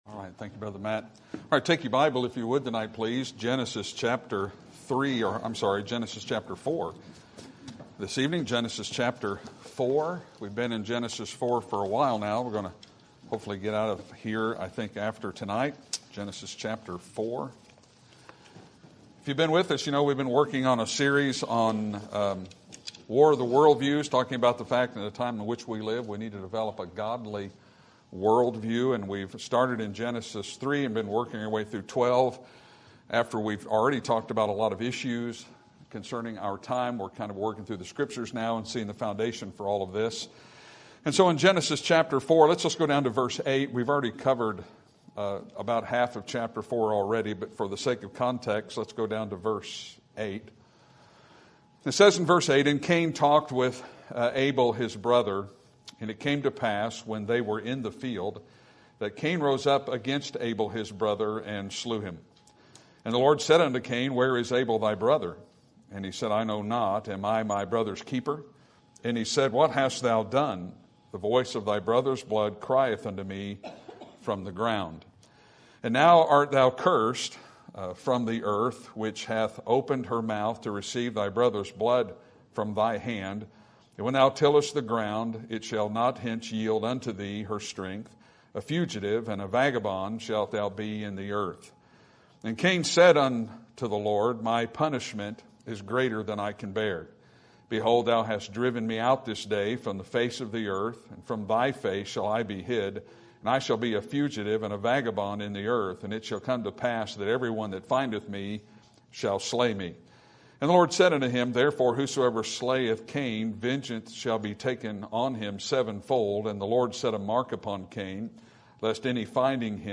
Sermon Date